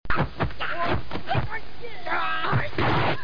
摔跤-体育运动-图秀网
图秀网摔跤频道，提供摔跤音频素材。